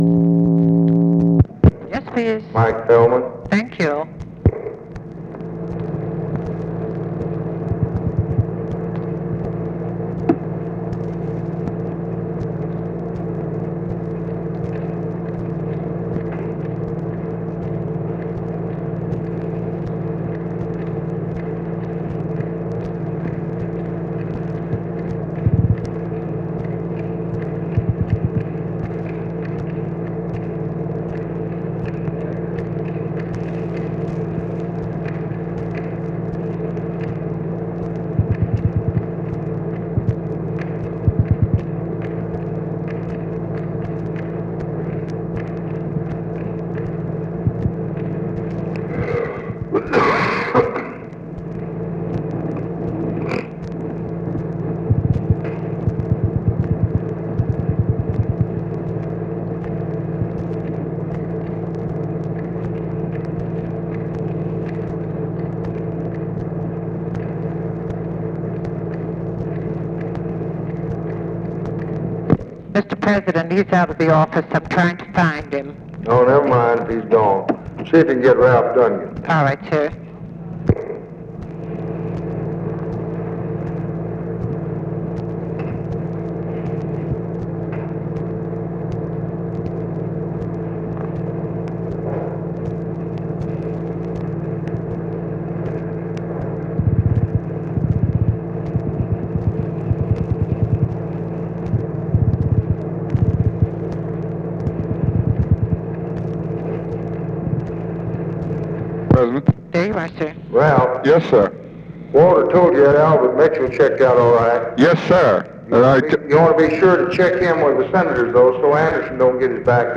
Conversation with RALPH DUNGAN and TELEPHONE OPERATOR, July 1, 1964
Secret White House Tapes